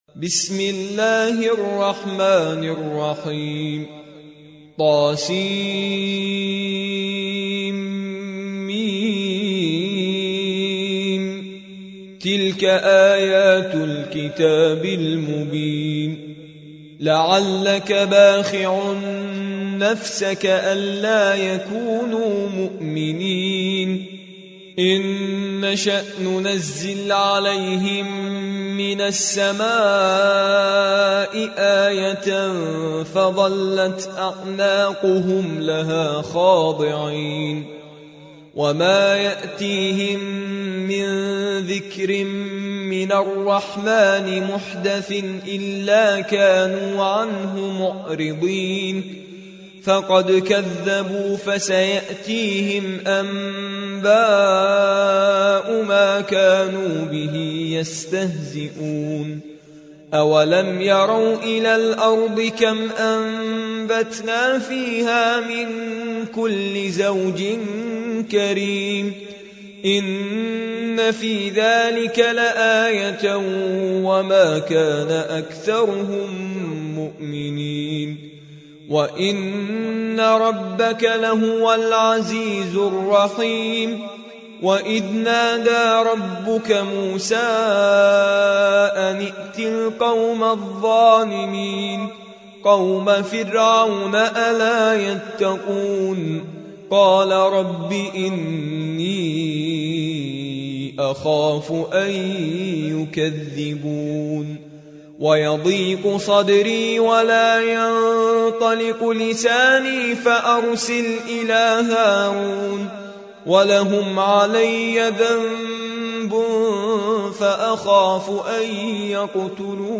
26. سورة الشعراء / القارئ